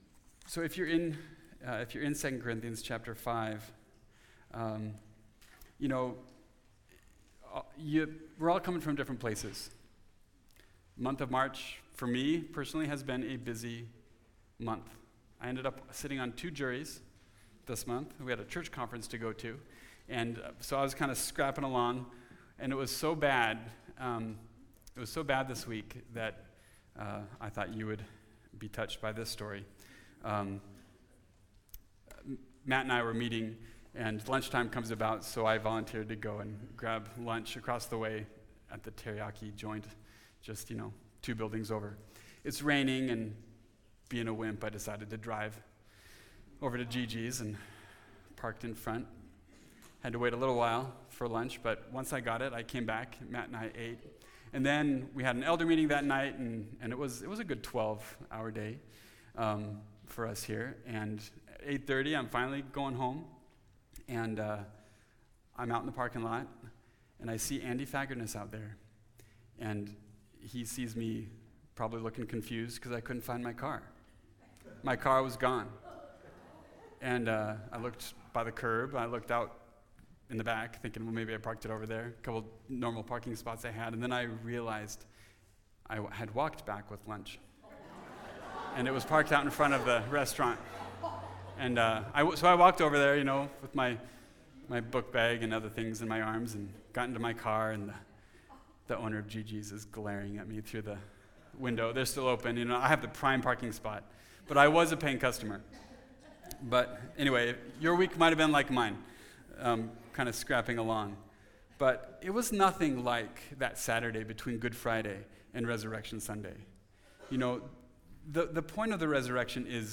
New Things Have Come (2 Corinthians 5:9-21) – Mountain View Baptist Church